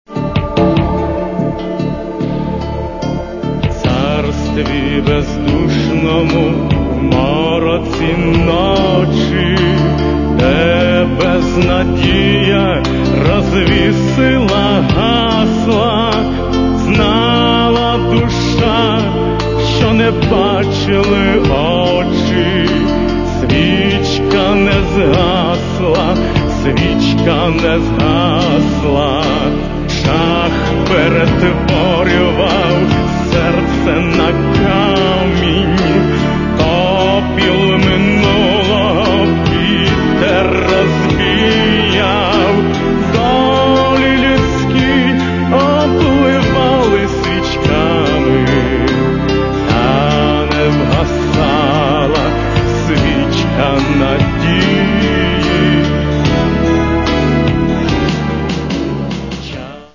Каталог -> Эстрада -> Поэты и композиторы